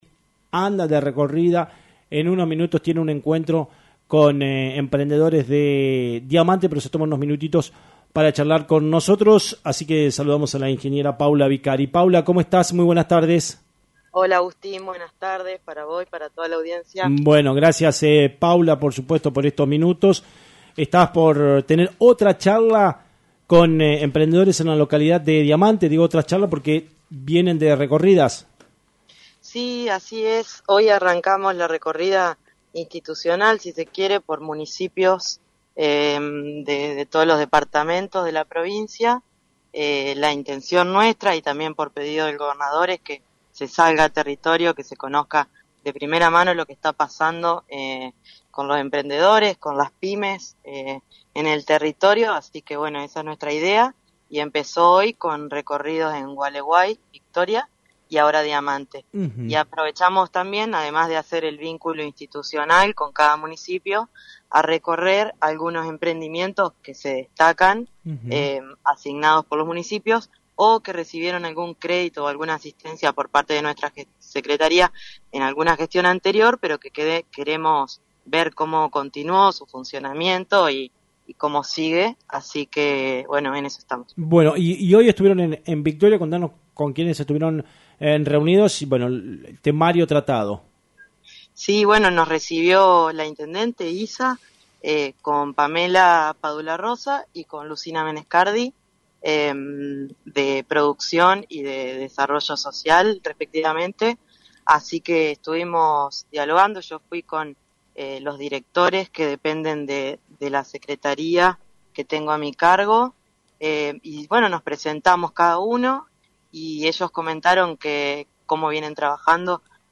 A pesar de su apretada agenda, Vicari se tomó unos minutos para conversar con LT39 antes de su próximo encuentro.